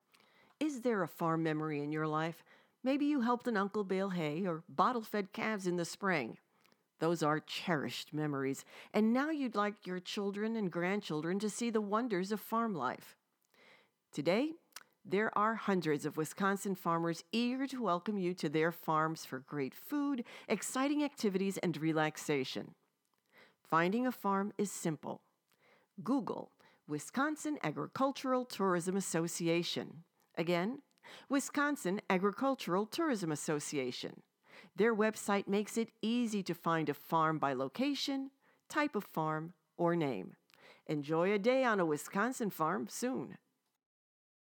Wisconsin Agricultural Tourism – Public Service Announcements (PSAs)